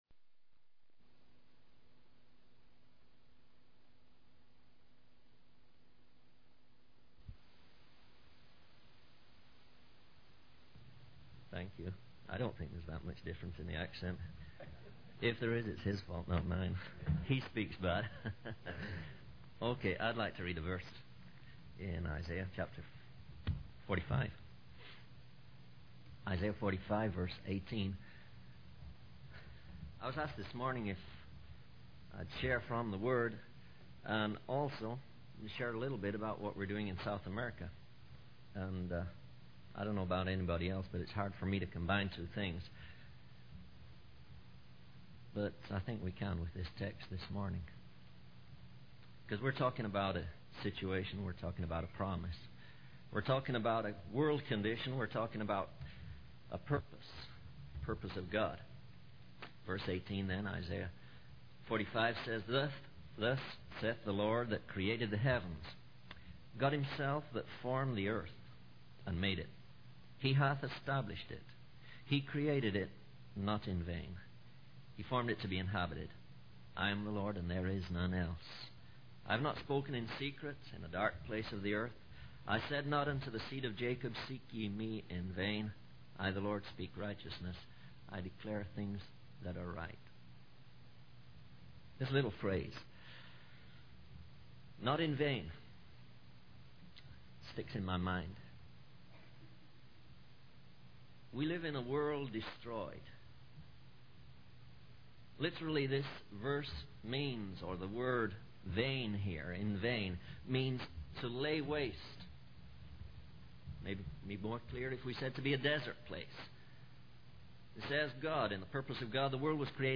In this sermon, the preacher emphasizes the message of repentance and the existence of a kingdom that surpasses our imagination. He highlights the curse of emptiness that hangs over nations and individuals and the need to replace it with fullness.